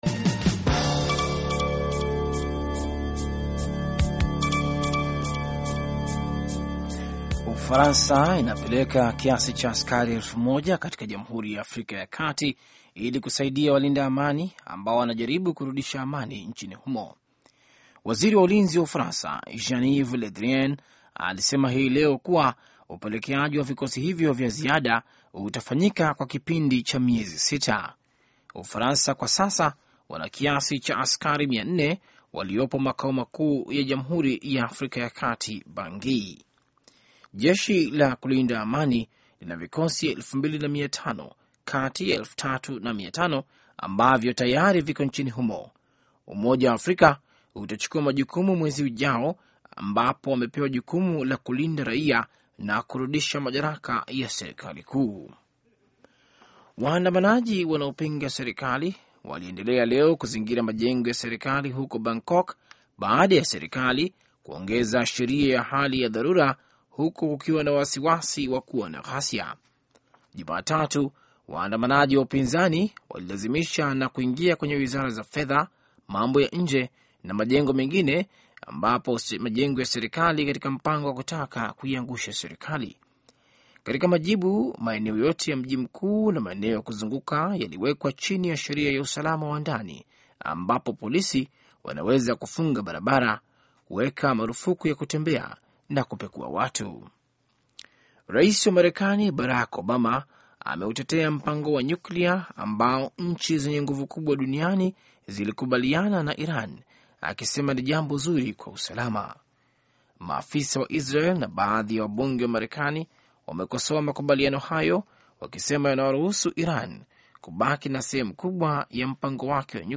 Taarifa ya Habari VOA Swahili - 4:54